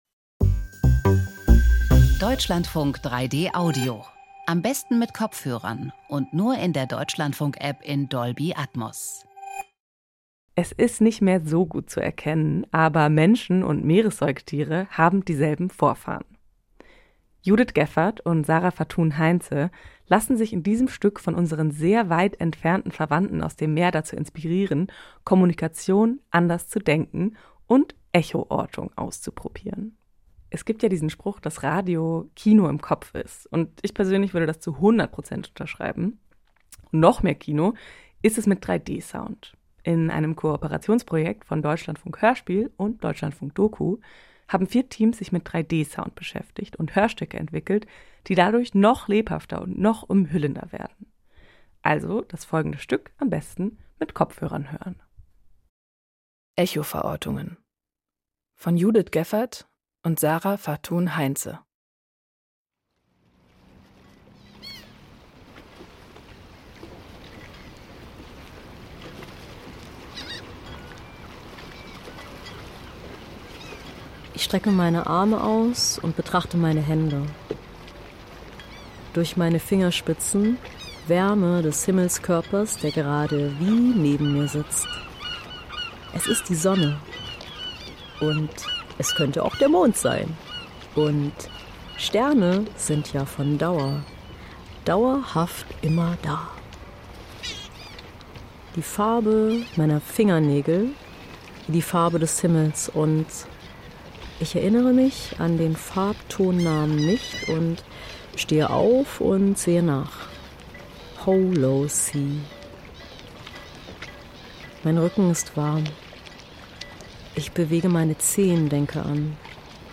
Feature Hörspiele und Dokus in 3D (2/4) Echo(ver)ortungen 13:20 Minuten Wie können Menschen und Meeressäugetiere miteinander kommunizieren? Eine Kontaktaufnahme in 3D Audio © Benjavisa